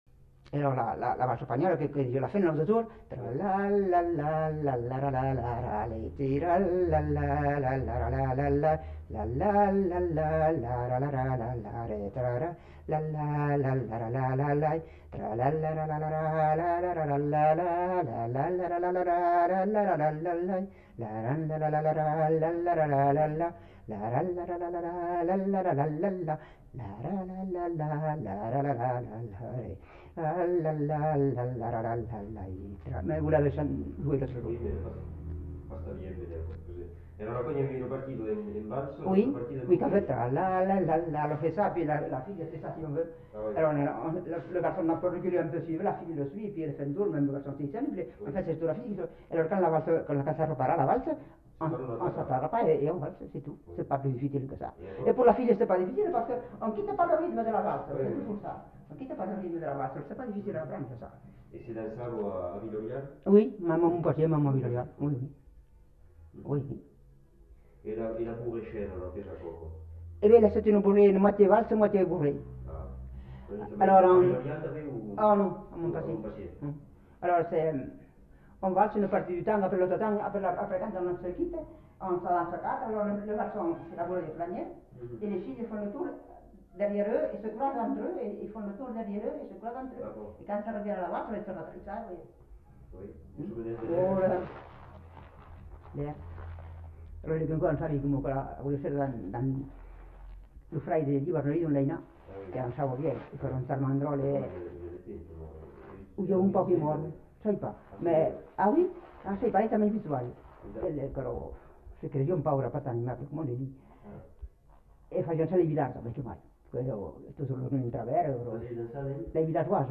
La valsa espanhòla (fredonné)
Lieu : Castillonnès
Genre : chant
Effectif : 1
Type de voix : voix de femme
Production du son : fredonné